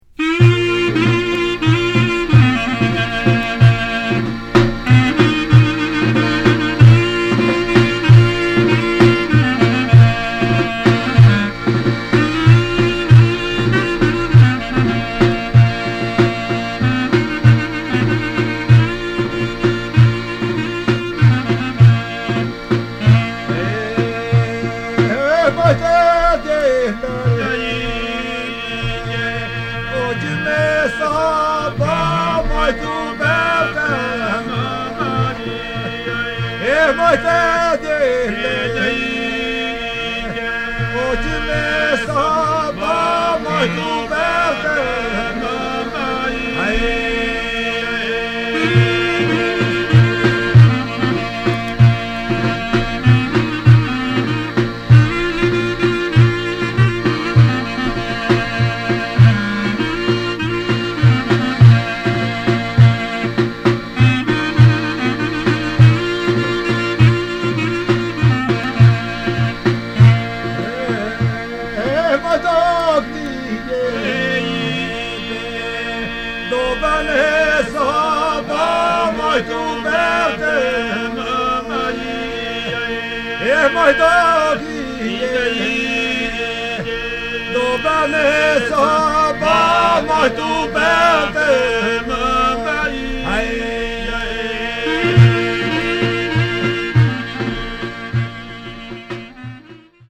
旧ユーゴスラヴィアのマケドニアで録音されたアルバニア人の音楽。
特異な多声音楽文化を持つことで知られるアルバニア人の中でも、異郷風情まんてんのポリフォニーを奏でるトスク人の音楽にフォーカス。
キーワード：Ocora　現地録り　ドローン